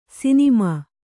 ♪ sinimā